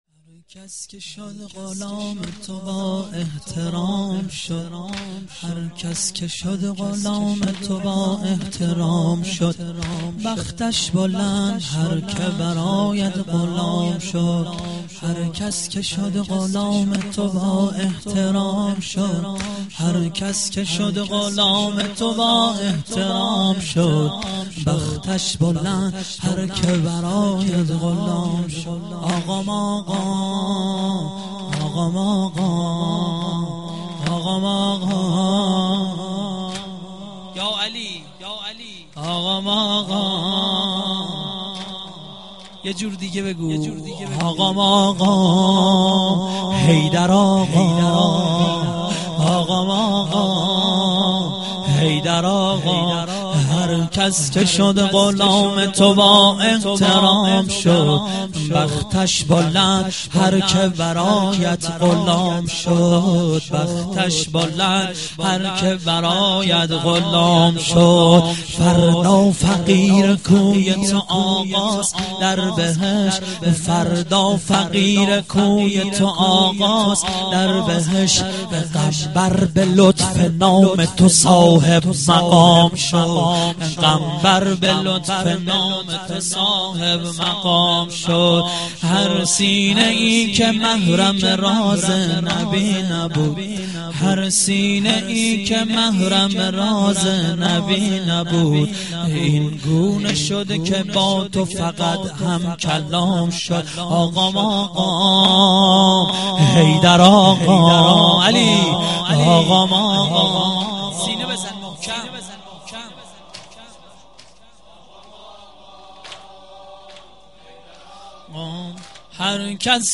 مراسم هفتگی 95.07.04